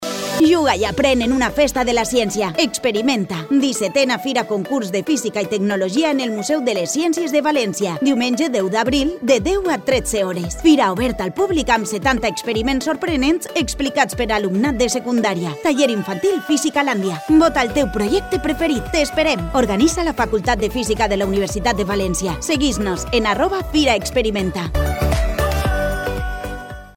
Cuña de radio